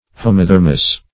Search Result for " homothermous" : The Collaborative International Dictionary of English v.0.48: Homothermic \Ho`mo*ther"mic\, Homothermous \Ho`mo*ther"mous\, a. [Homo- + Gr.